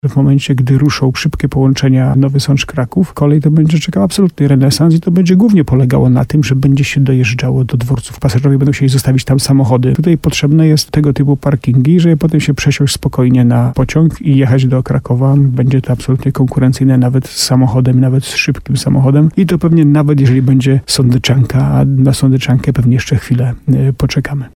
Burmistrz Jacek Lelek, który był gościem programu Słowo za Słowo na antenie RDN Nowy Sącz, liczy, że po uruchomieniu zmodernizowanej linii 104 będzie to bardzo oblegany parking.